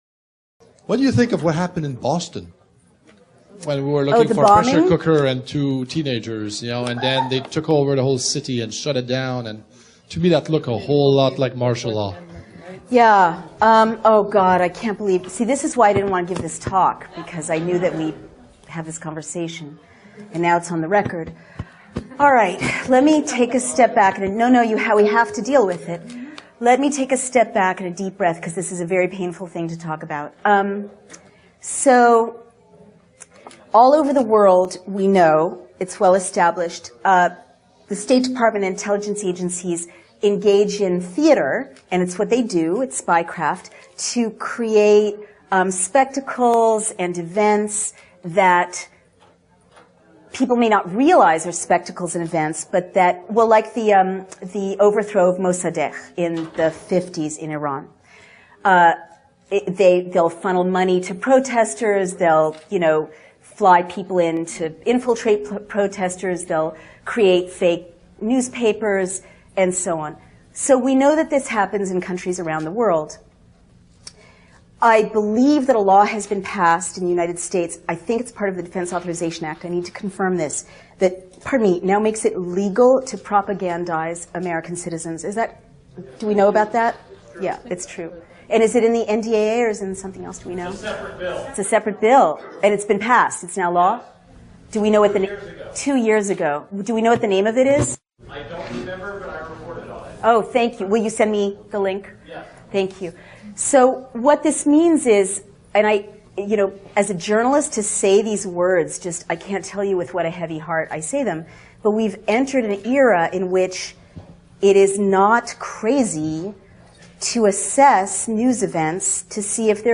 This took place at the 2014 Free State Project’s Liberty Forum.